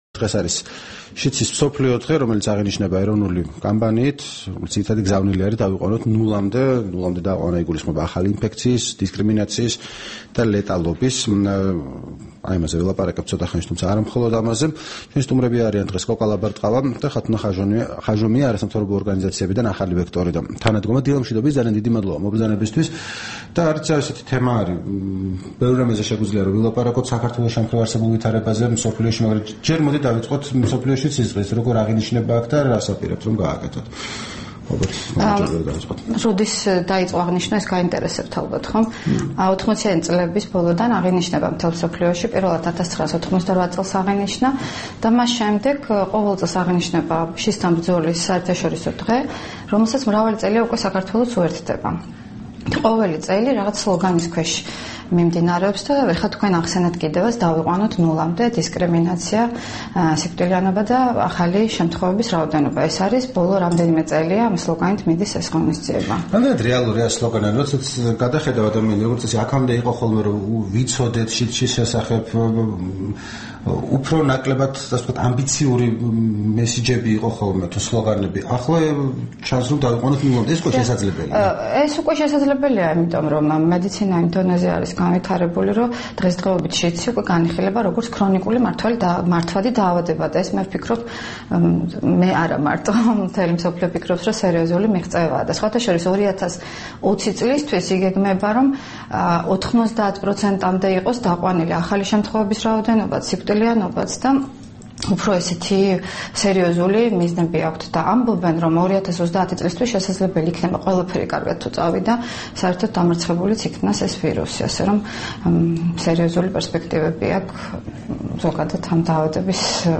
სტუმრად ჩვენს ეთერში
საუბარი